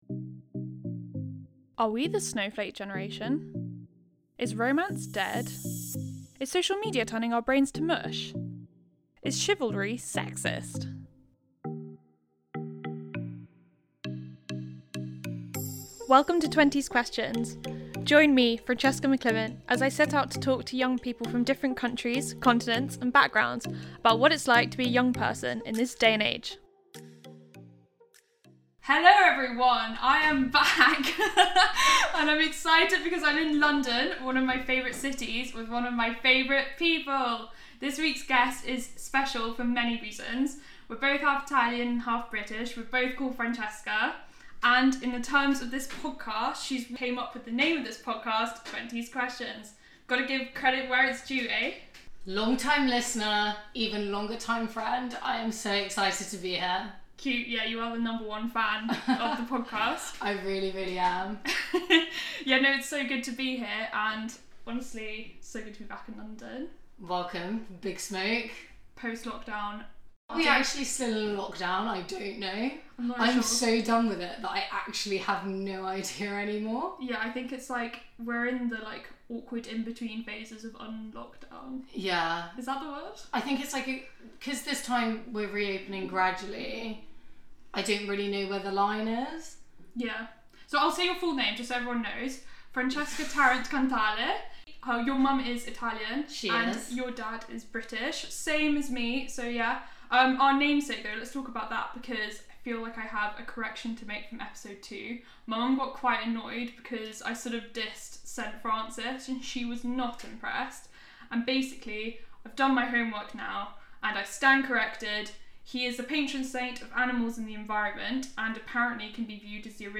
It was so fun to record this in person with her, particularly since the last coupl…